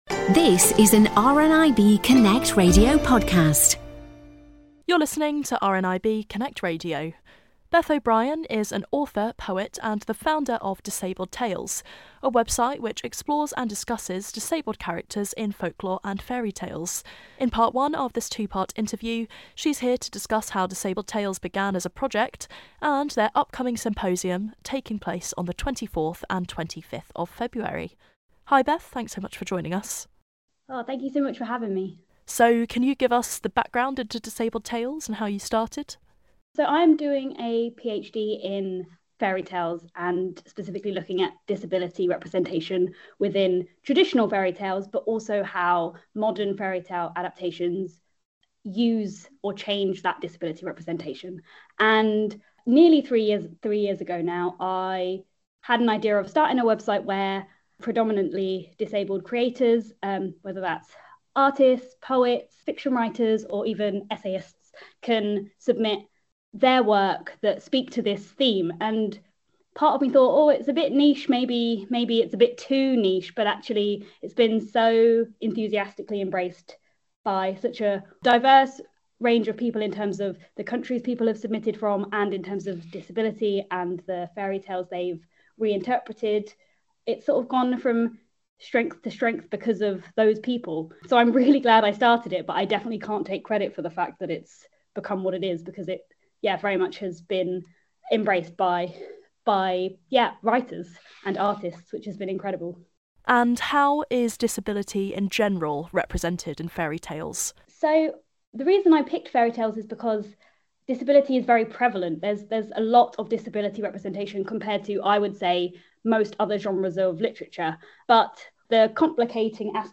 In part 1 of this 2 part interview